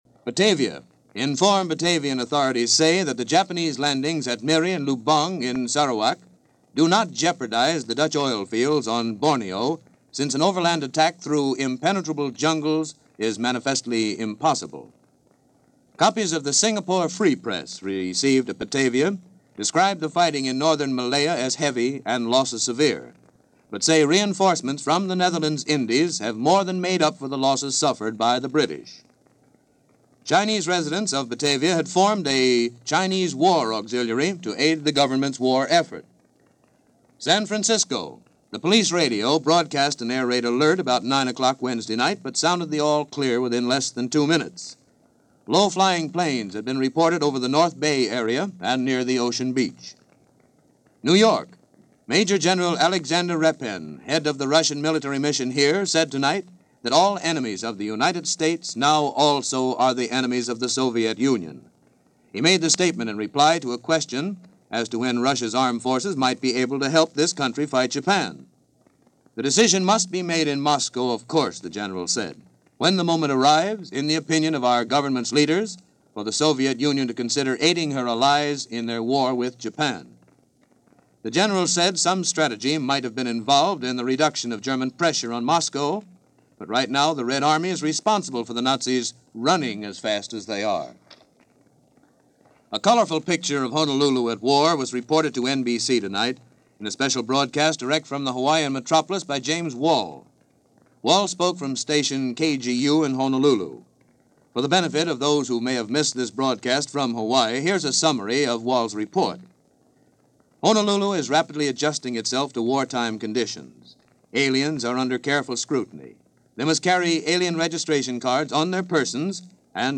The Rhythm Of War - Scrutinizing Aliens - Introducing War Bonds - December 18, 1941 - NBC News Of The World